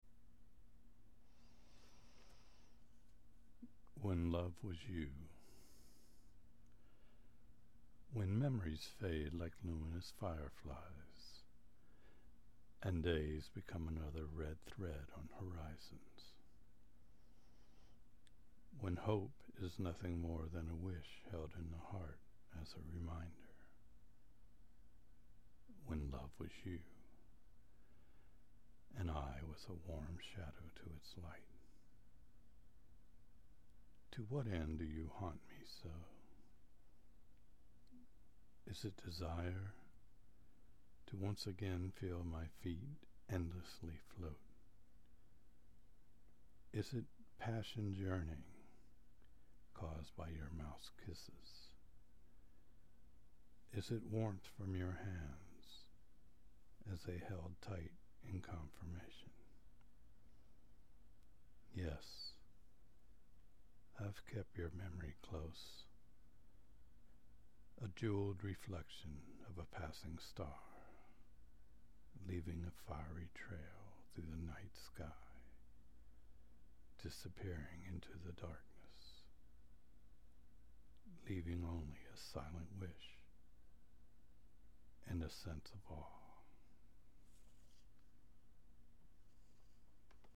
This time I could hear your reading, and I FAVE This one.